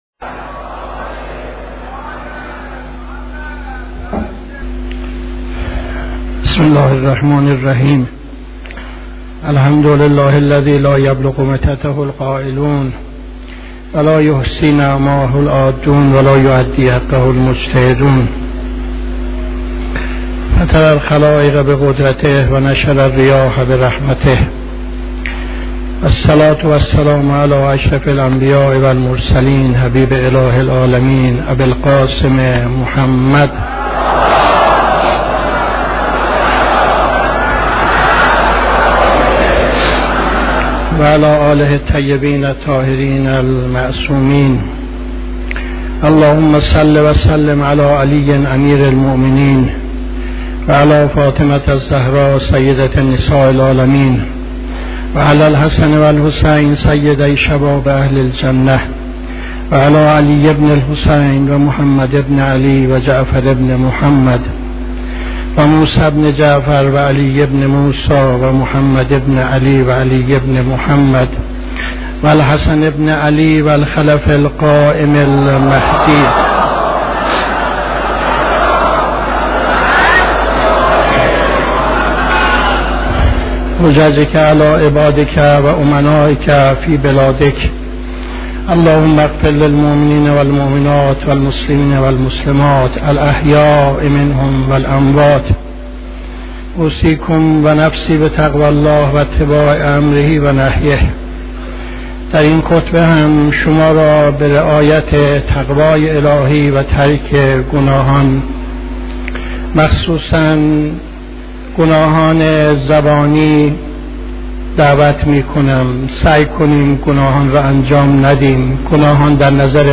خطبه دوم نماز جمعه 26-02-76